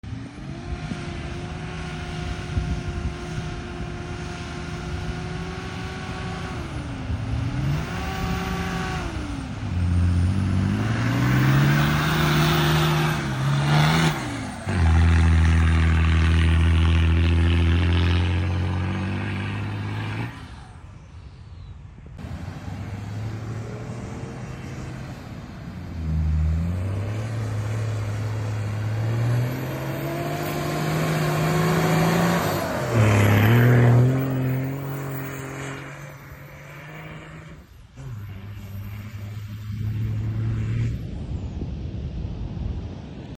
Couple old forklifts earning their sound effects free download
Couple old forklifts earning their keep on the beach